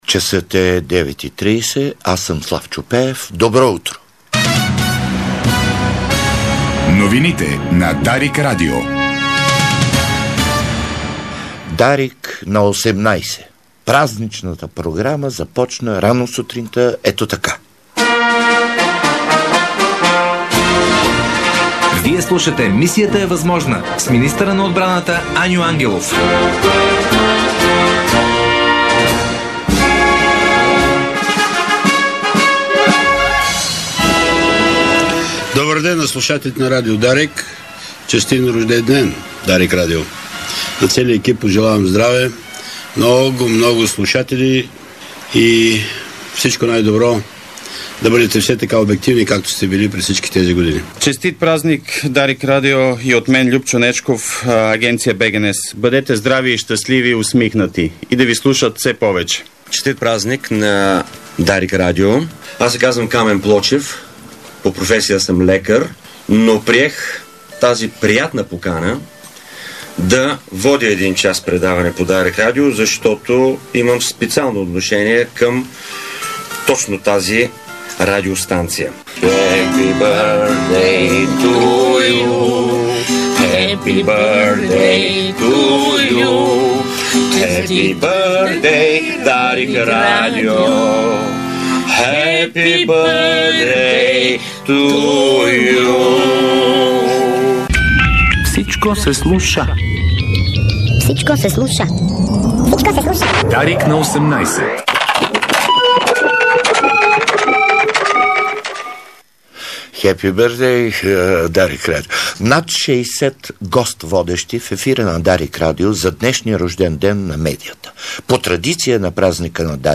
Емисия новини в 09.30 с актьора Славчо Пеев